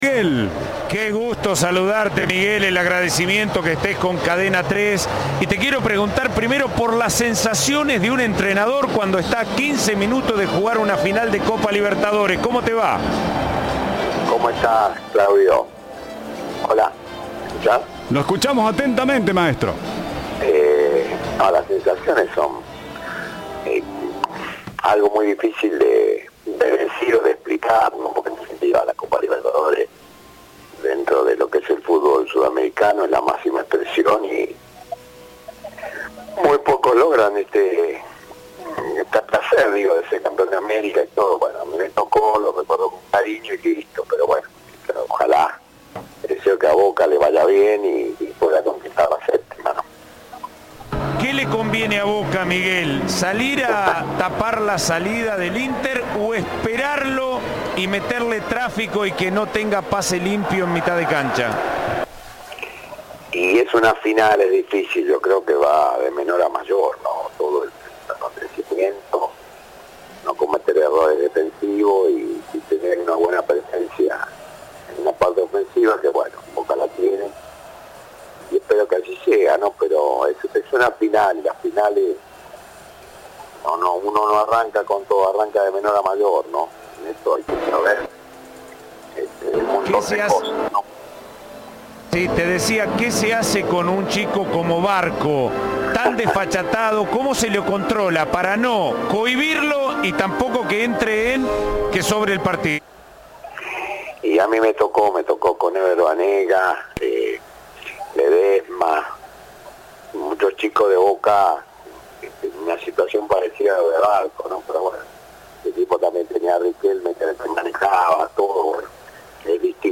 El director técnico del "Xeneize" en la obtención de la última Libertadores habló con la Gran Cadena Federal en la previa del partido ante Fluminense en el Maracaná.